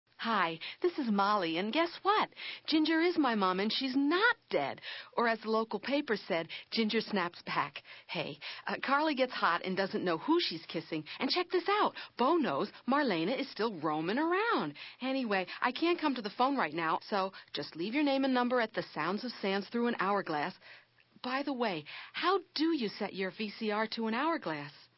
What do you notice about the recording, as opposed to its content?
Because the cassette these recordings were archived from was not properly labeled.